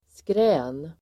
Ladda ner uttalet
skrän substantiv, howl , yell Uttal: [skrä:n] Böjningar: skränet, skrän, skränen Synonymer: oljud, oväsen, skrål, vrål Definition: gällt (oartikulerat) rop (från flera röster) Avledningar: skränig (noisy)